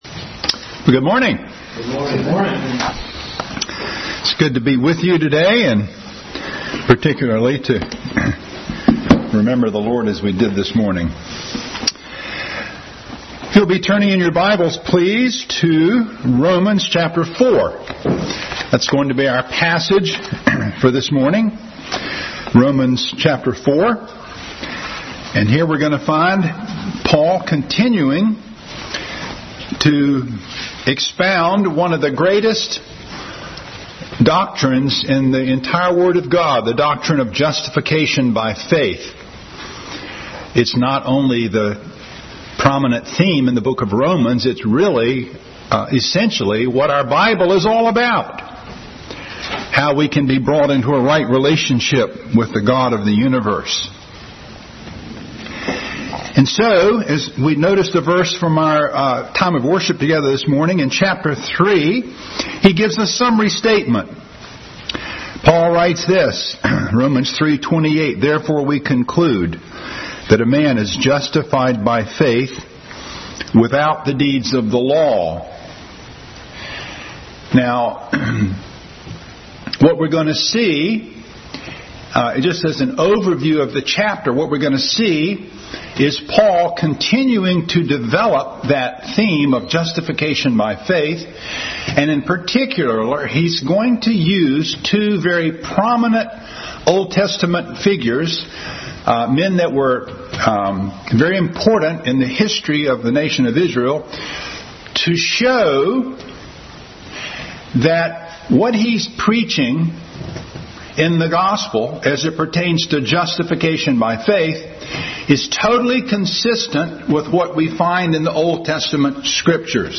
Bible Text: Romans 4:1-8, Romans 3:28, Job 9:2, Romans 1:16-17, Deuteronomy 25:1, Proverbs 17:15, Genesis 12:2-3, 15:5-6, Psalm 32:1-2 | Adult Sunday School continued study in the book of Romans.
Service Type: Sunday School